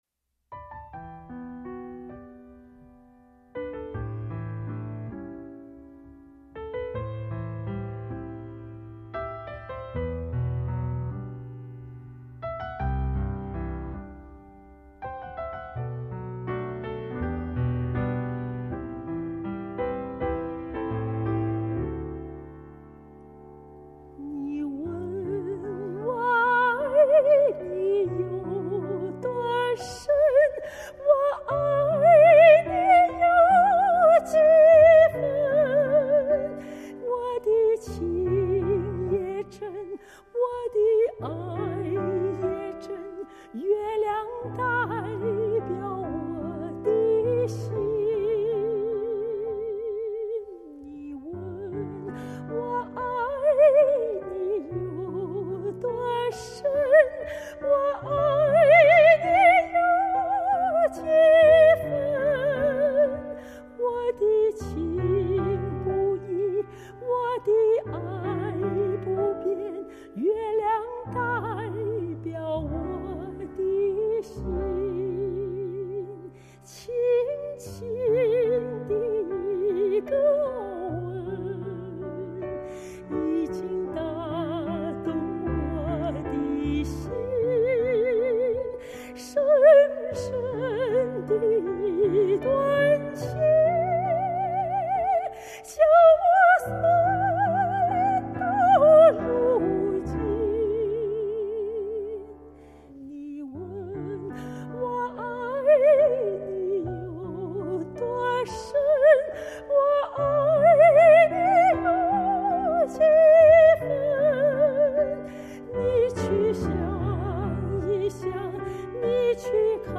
钢琴伴奏